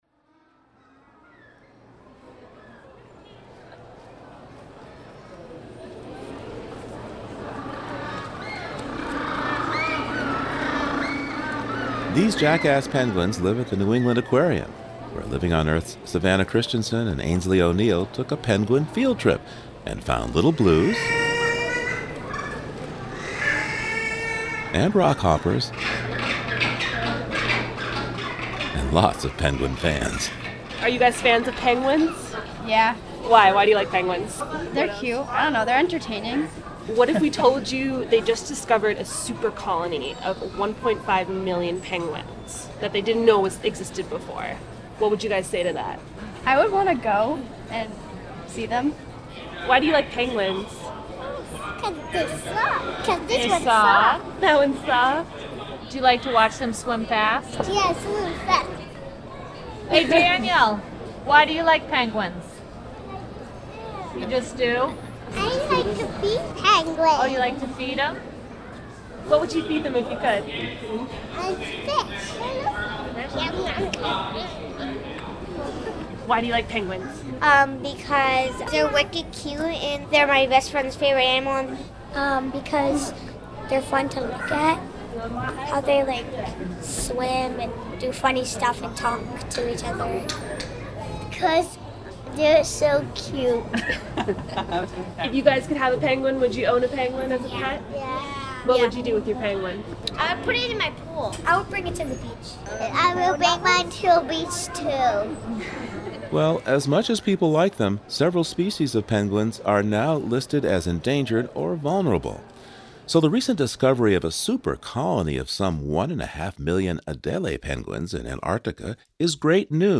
[SOUNDS OF JACKASS PENGUINS AT AQUARIUM]